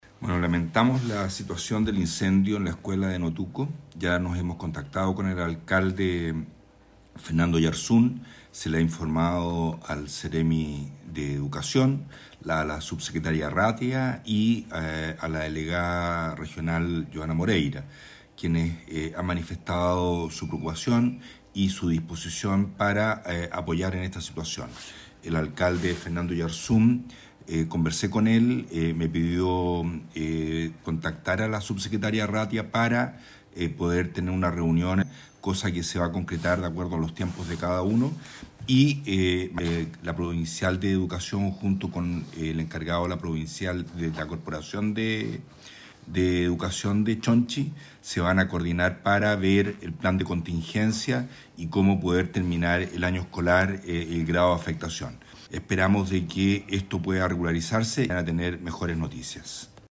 También acerca de posibles iniciativas para entregar una solución en el mediano plazo, señaló el delegado provincial Marcelo Malagueño, quien confirmó que ya existen gestiones ante las autoridades del ministerio de Educación y del Ejecutivo en la región de Los Lagos.
04-DELEGADO-INCENDIO-ESCUELA-NOTUCO.mp3